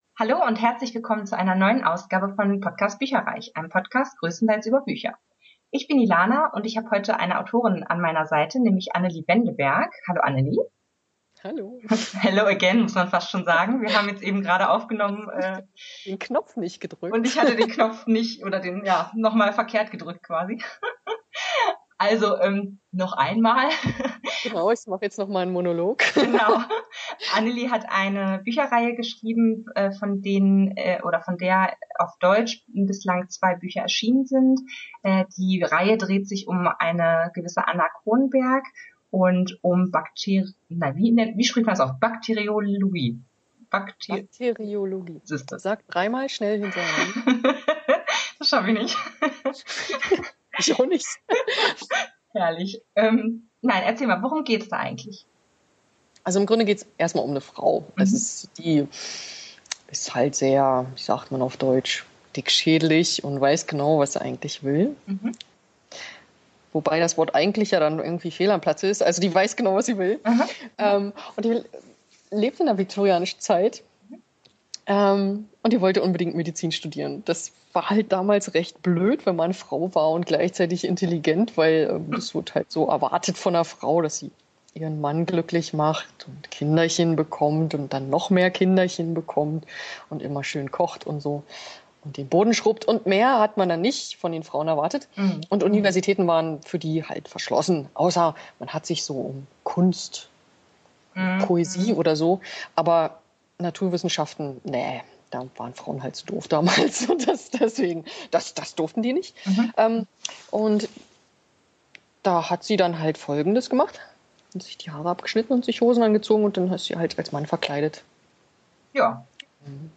Beschreibung vor 10 Jahren Willkommen bei bücherreich, einem Podcast größtenteils über Bücher!
Entschuldigt bitte, dass die Tonqualität leider nicht auf dem üblichen Niveau ist, da das Interview via Skype geführt und aufgenommen wurde.